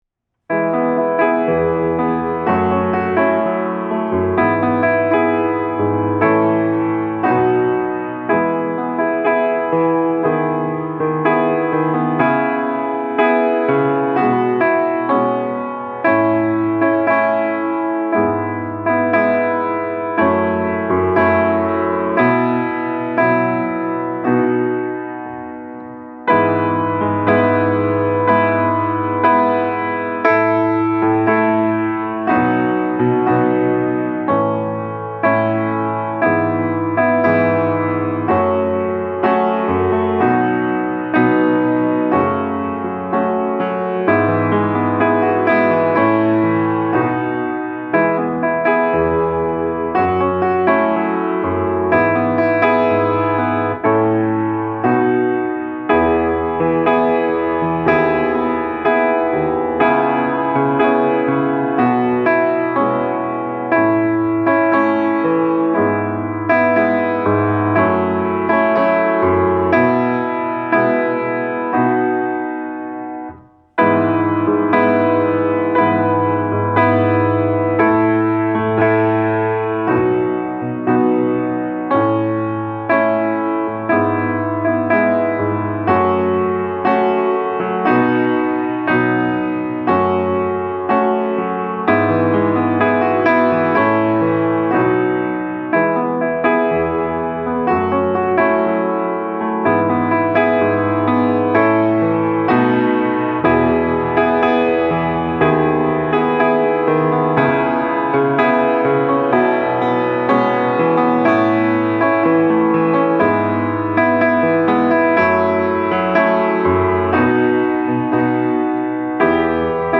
Piano Accompaniment
Piano